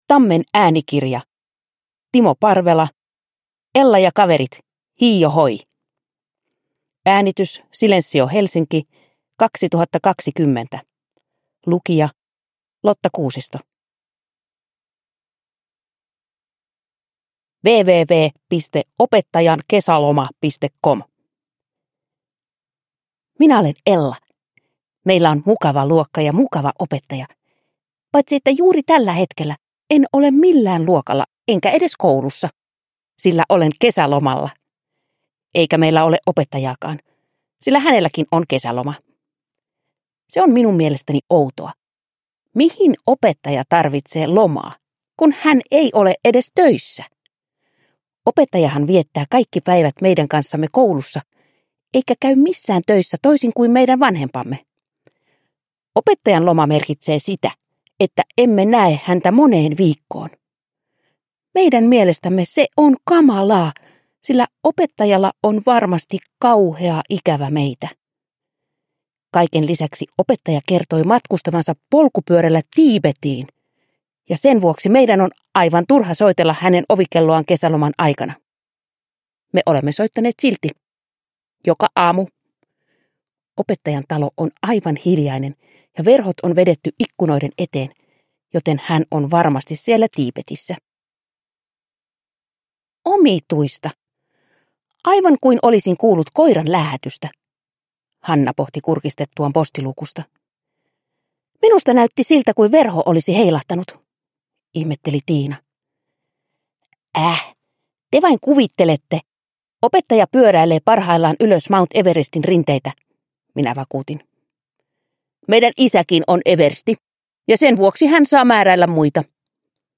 Ella ja kaverit hiiohoi! – Ljudbok – Laddas ner